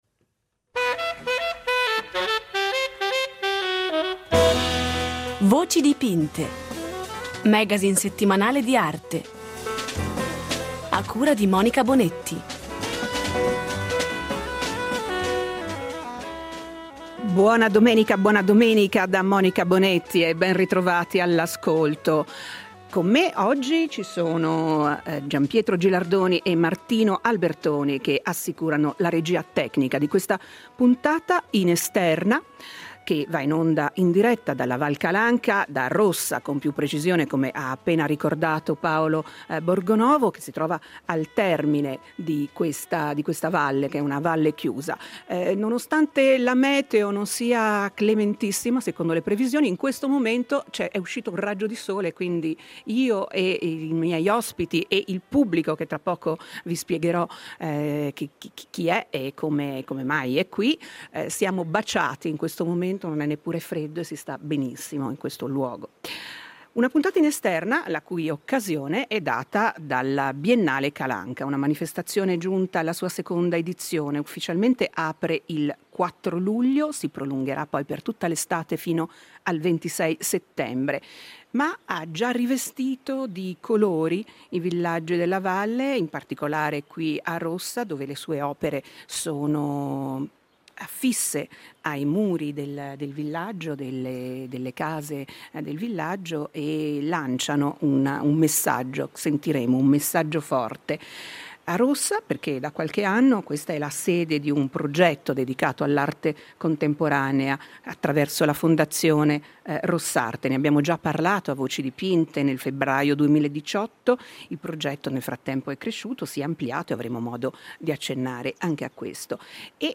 Nella sua ultima trasmissione della stagione "Voci Dipinte" è in trasferta a Rossa per raccontare il lavoro della Biennale e questo felice connubio tra locale e globale.